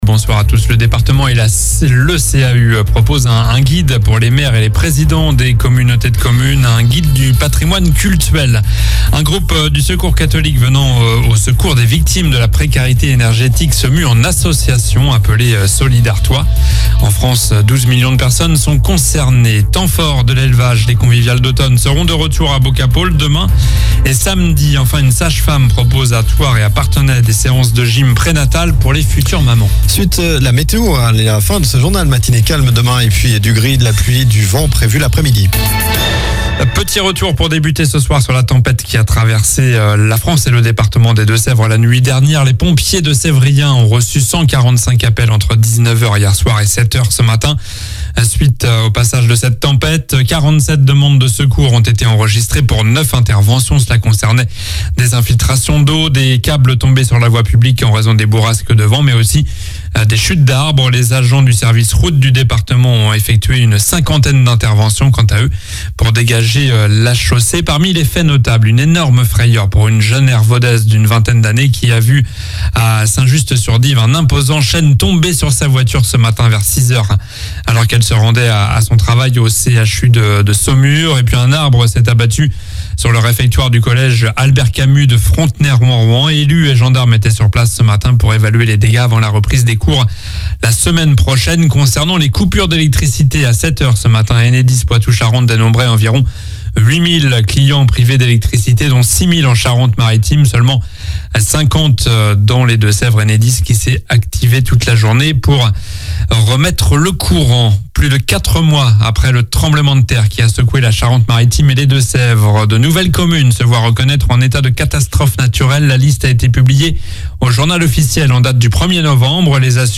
Journal du jeudi 02 novembre (soir)